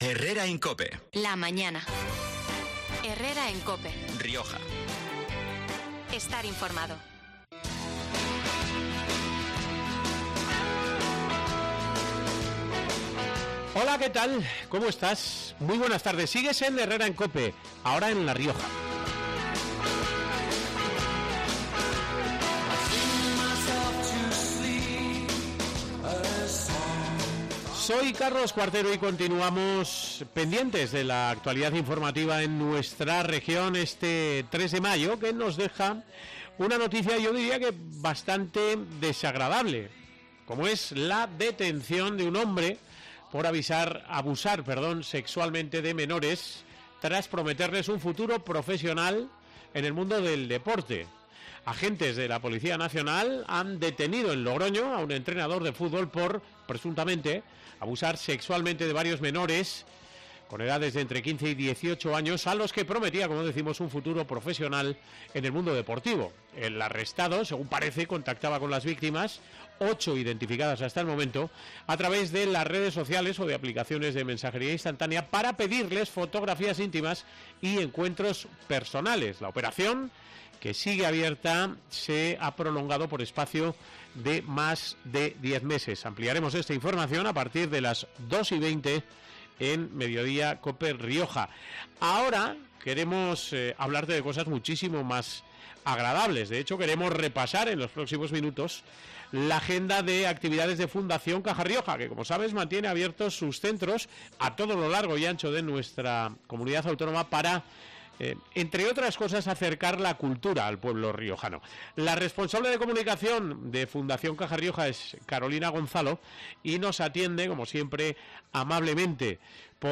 Este 3 de mayo hemos repasado en COPE Rioja la agenda de actividades de Fundación Cajarioja, que mantiene abiertos sus centros a todo lo largo y ancho de nuestra región, para acercar la cultura al pueblo riojano.